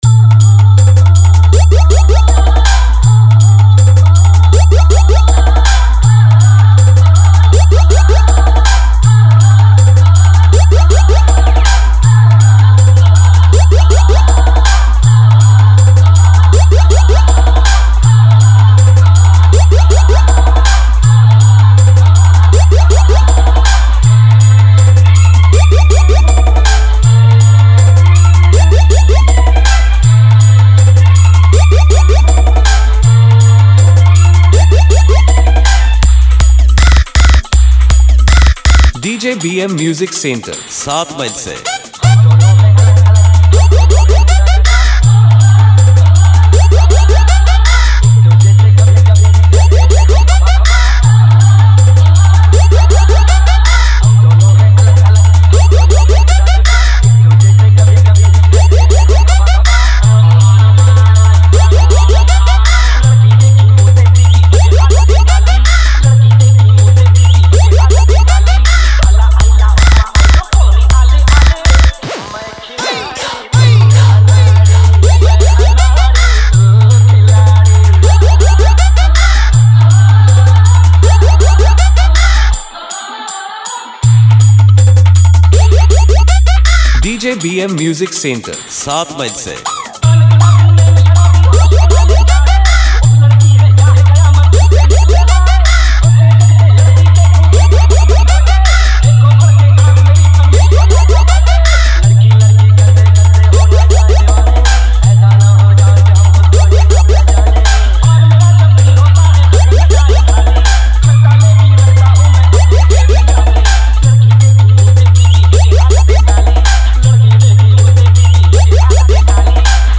New Style Competition 1 Step Long Humming Pop Bass Mix 2025